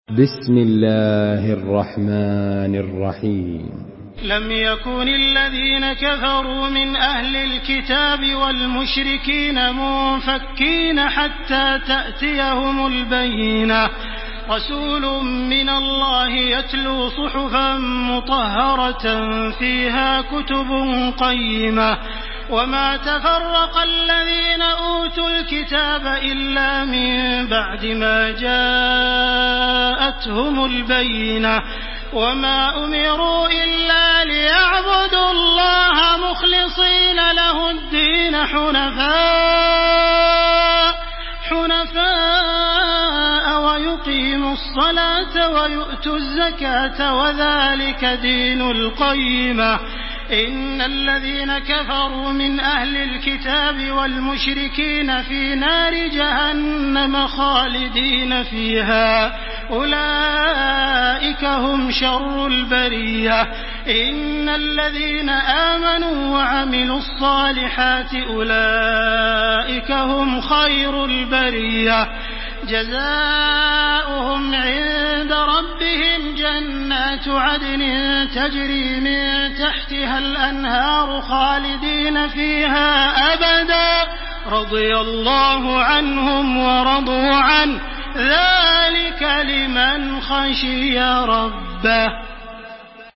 تحميل سورة البينة بصوت تراويح الحرم المكي 1429
مرتل حفص عن عاصم